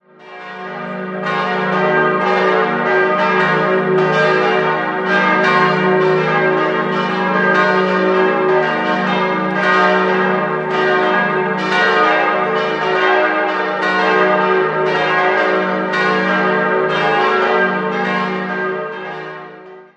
In den Jahren 1952 bis 1954 entstand in Stahlbeton-Rasterbauweise nach den Plänen von Hans Beckers die Kirche Mater Dolorosa, welche mittlerweile sogar denkmalgeschützt ist. Die große Kostbarkeit im Inneren ist der Renaissance-Hochaltar aus dem Jahr 1620, der früher in der Kreuzkapelle des ehemaligen Klosters Geisenfeld stand. 4-stimmiges ausgefülltes Es-Moll-Geläute: es'-ges'-as'-b' Alle Glocken wurden 1956 von der Gießerei Hofweber in Regensburg gegossen.